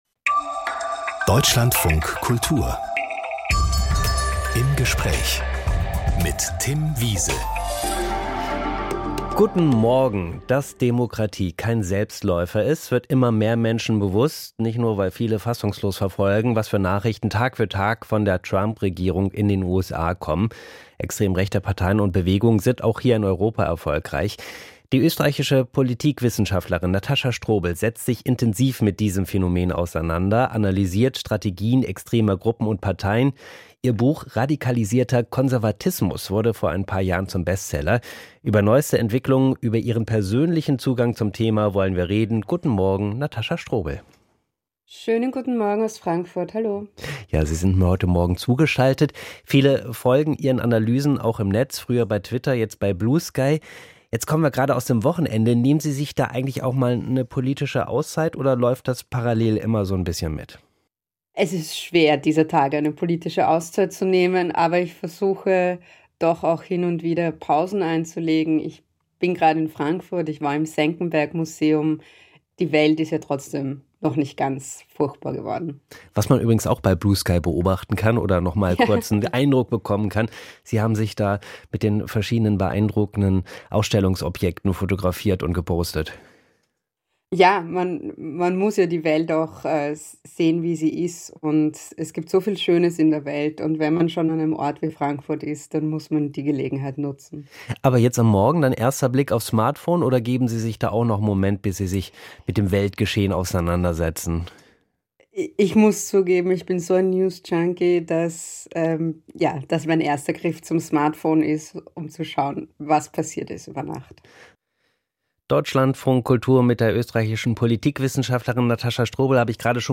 Aus dem Podcast Im Gespräch Podcast abonnieren Podcast hören Podcast Im Gespräch Eine ganze Stunde widmen wir einer Person.